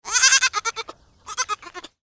جلوه های صوتی
دانلود صدای بز 4 از ساعد نیوز با لینک مستقیم و کیفیت بالا